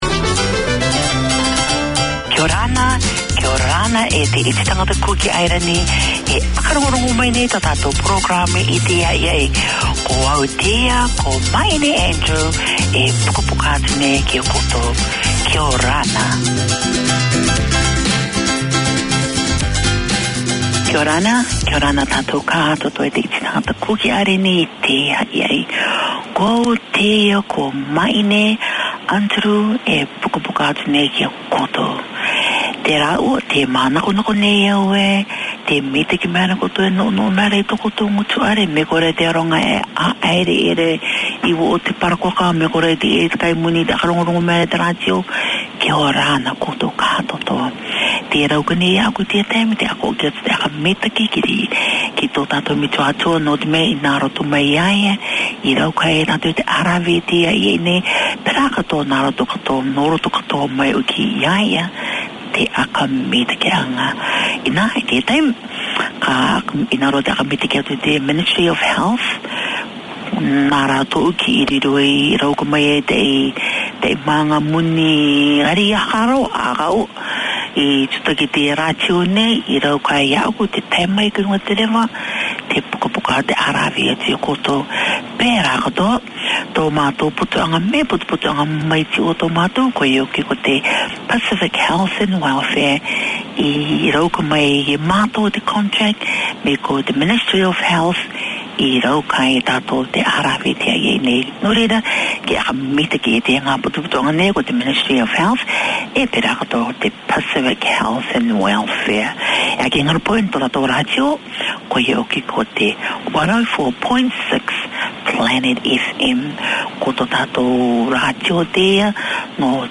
Community Access Radio in your language - available for download five minutes after broadcast.
Pasifika Wire 4:50pm SATURDAY Community magazine Language: English Pasifika Wire Live is a talanoa/chat show featuring people and topics of interest to Pasifika and the wider community.